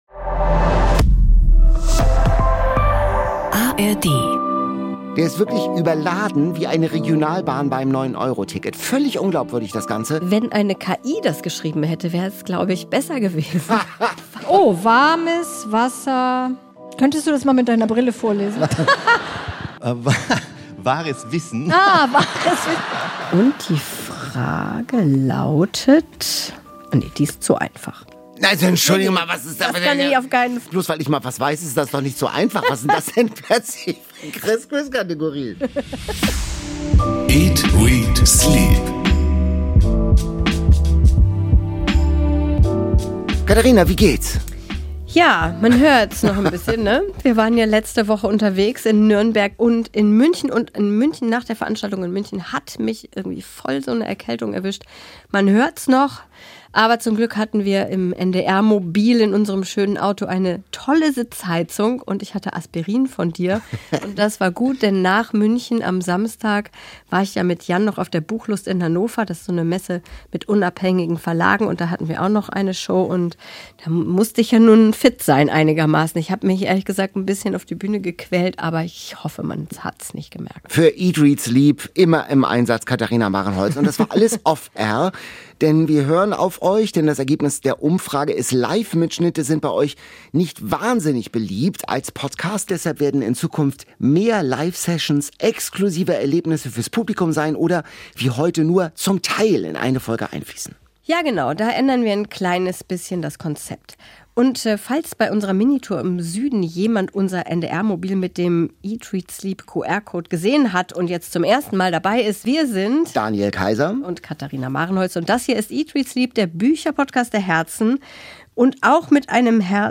Krimi-Special! Was taugt der neue Fitzek, wie kann man in Hotelzimmer einbrechen, warum mordet es sich im Eis besonders gut? Zu Gast ist diesmal Bestseller-Autor Bernhard Aichner, der nicht nur spannende Plots beherrscht, sondern auch Glückskekse backen kann.